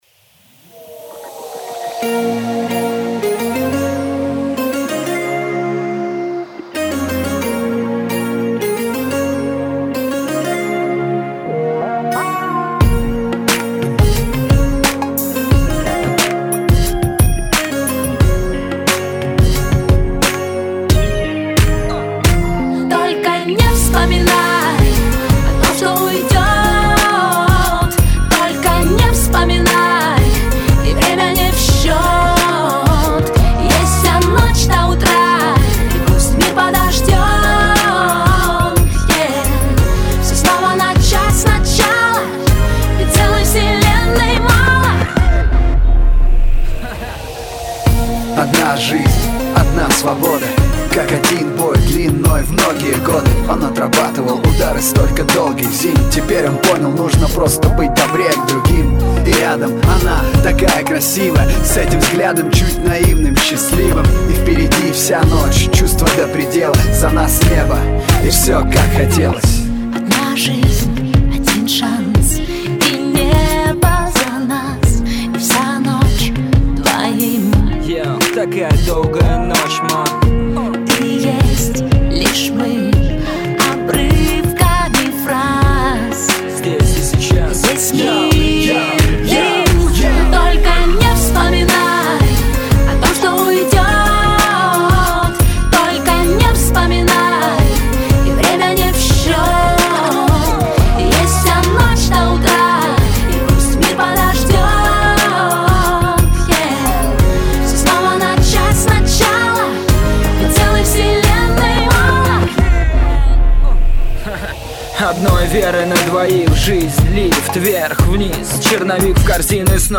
Категория: RnB